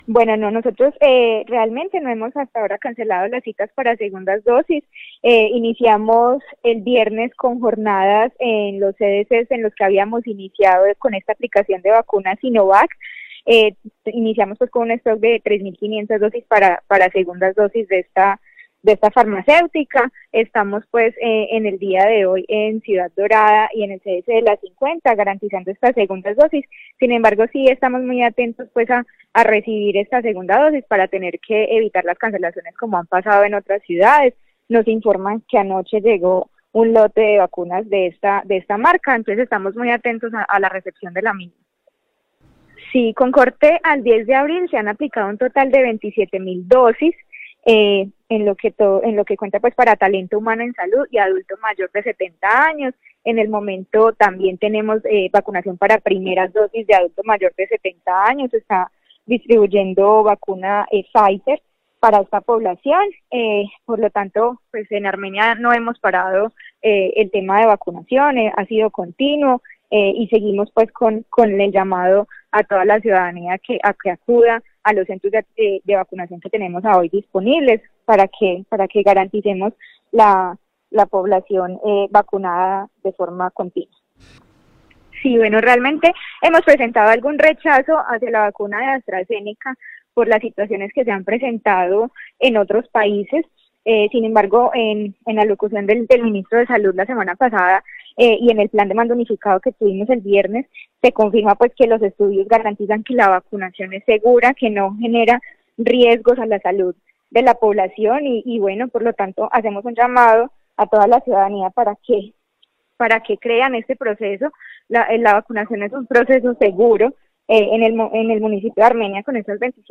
Audio: Lina María Gil Tovar, Secretaria de Salud